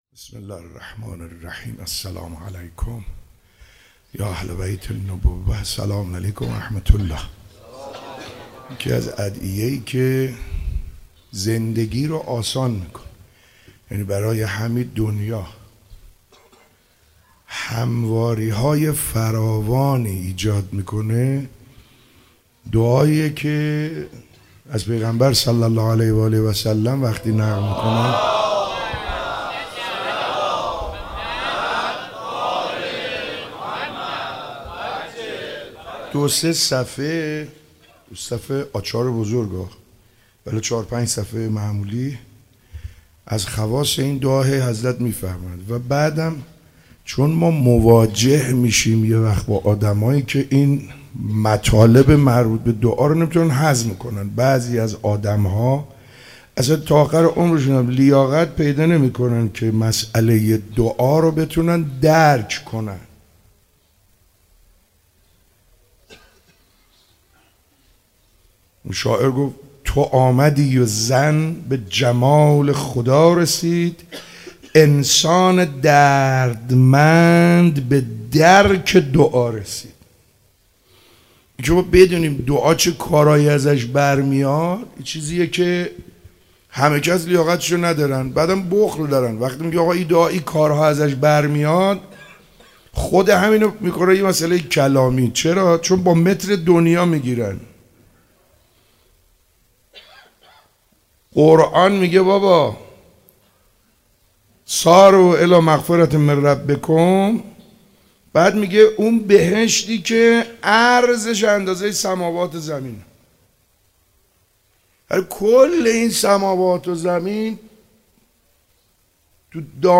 فاطمیه 95_ روز دوم_صحبت